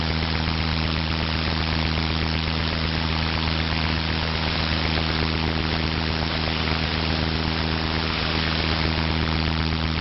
Radar_or_QRM_80Hz.mp3